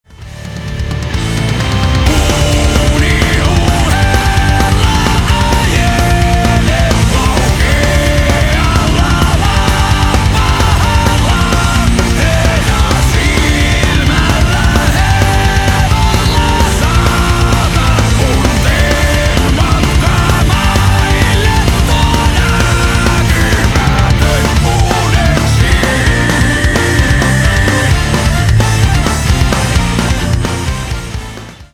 • Качество: 320, Stereo
мужской вокал
быстрые
Folk Rock
Metal
эпичные